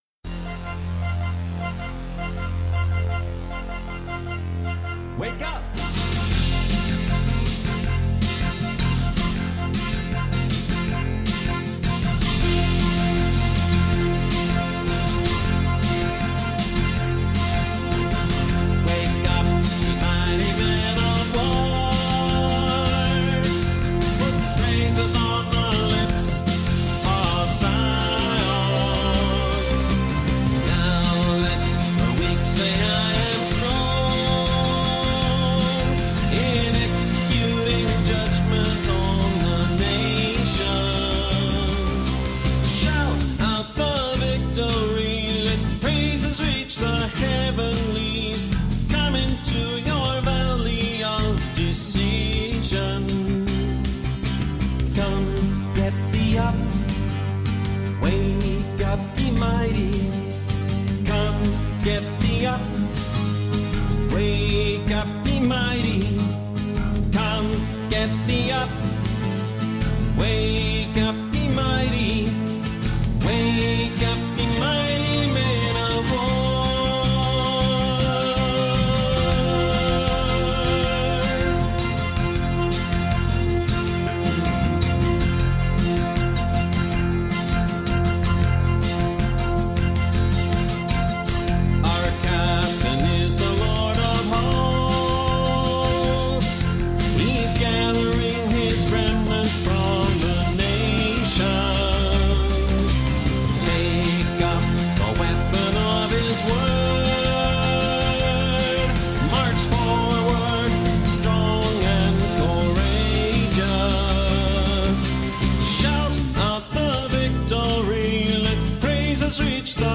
rams horns